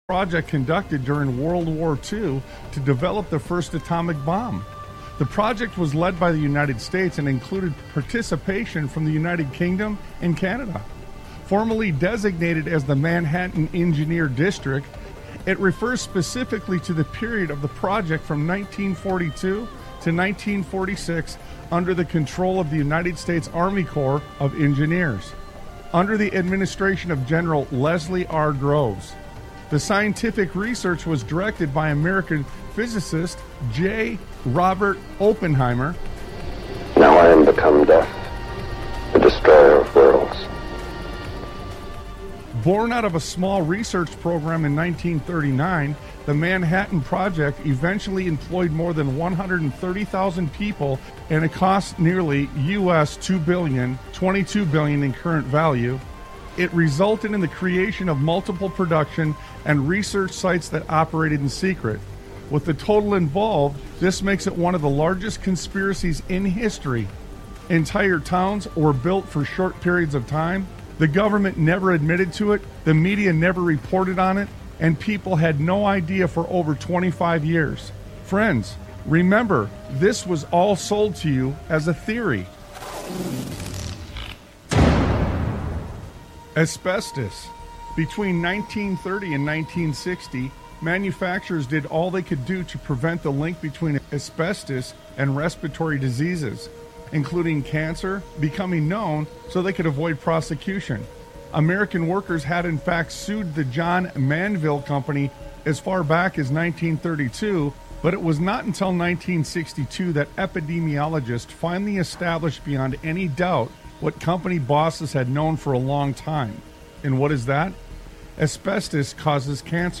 Talk Show Episode, Audio Podcast, Sons of Liberty Radio and Are Americans Being Lied To, Again?